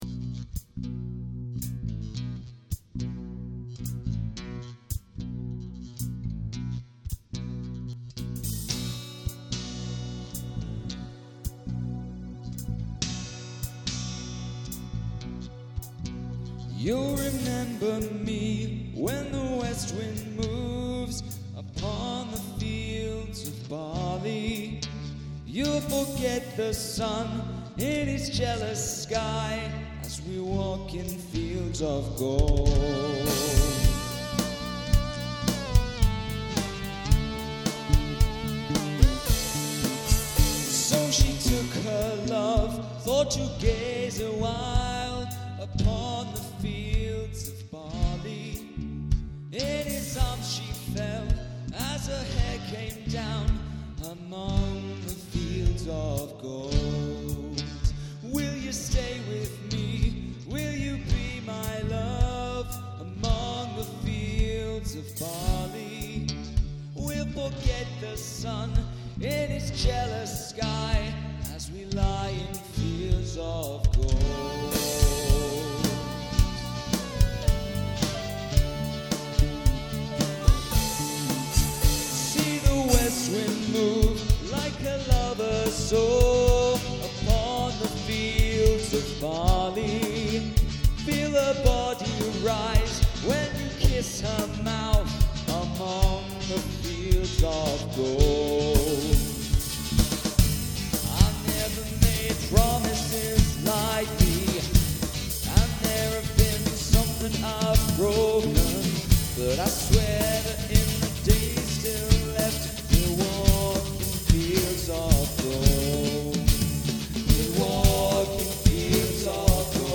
nostalgia